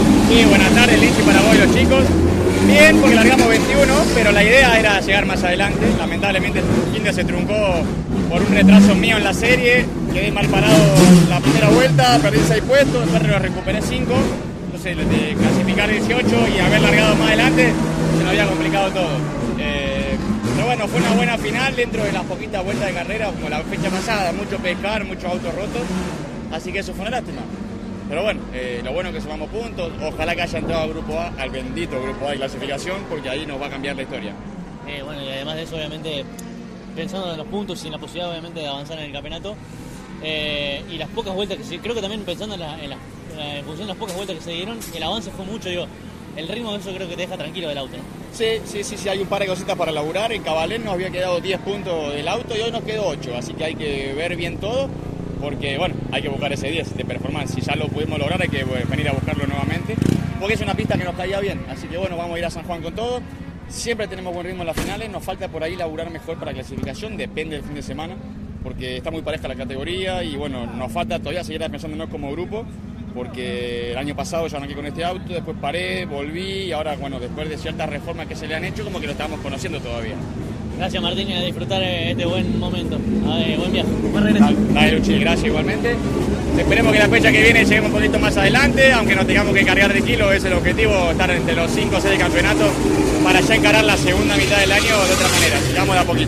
CÓRDOBA COMPETICIÓN estuvo presente en el evento y, al término de la competencia definitiva de la divisional menor, dialogó con cada uno de los protagonistas del podio, así como también del cordobés mejor ubicado al término de la prueba.